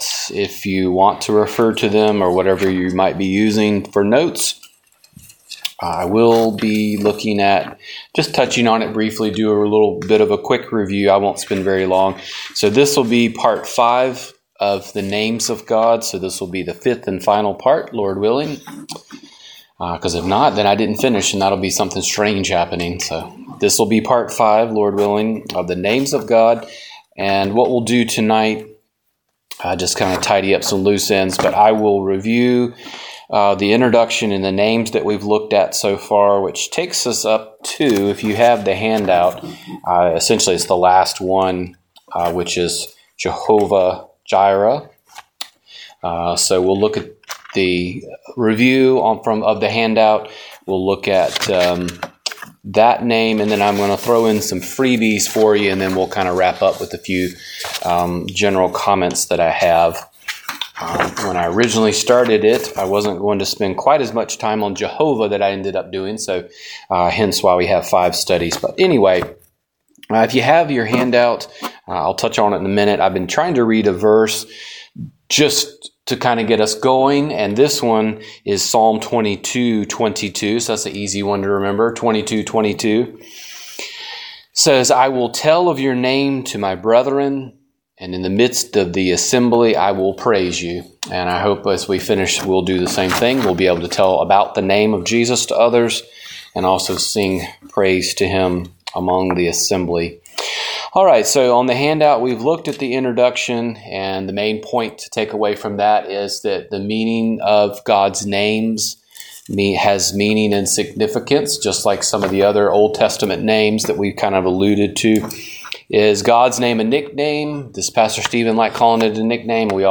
Download Download The Names of God - Part 5 Wed. Night Bible Study The Life of Stephen - Part 7 Wed.